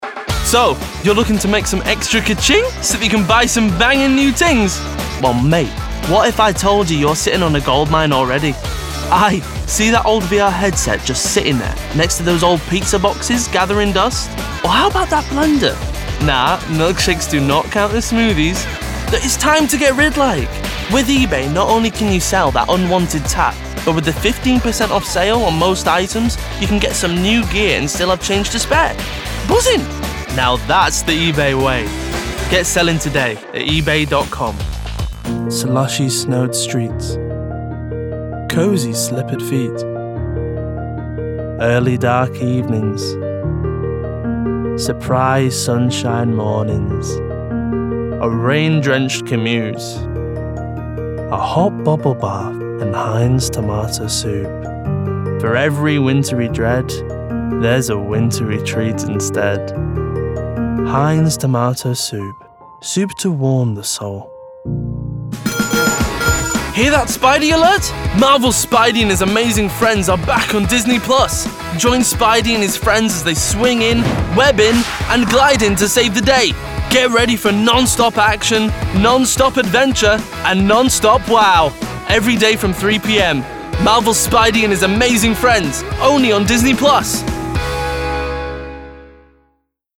Voice Reel
Commercial Montage